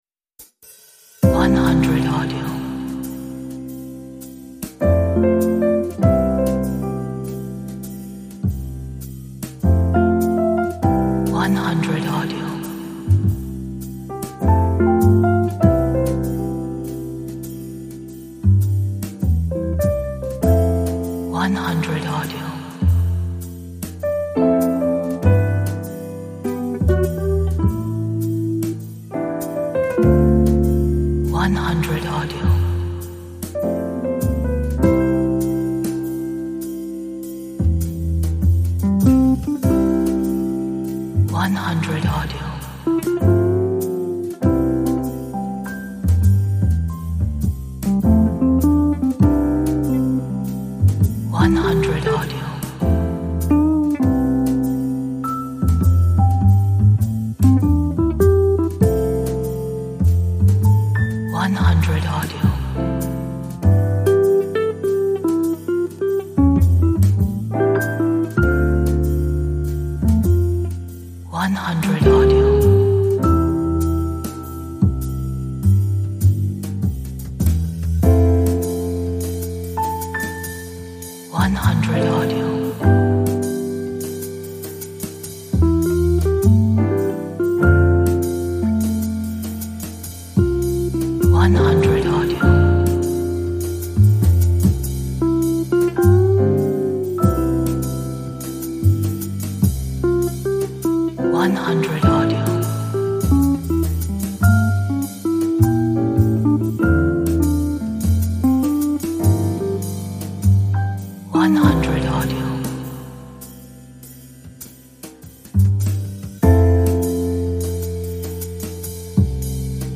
Light jazz composition in blues style.